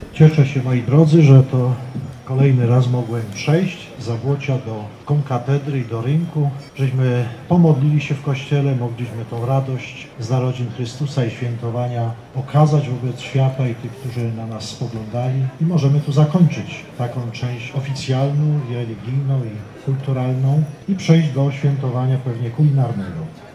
To ważne, że chcemy się radować w taki sposób, mówił ze sceny ks. bp. Roman Pindel, który przewodniczył mszy w Zabłociu i brał udział w orszaku.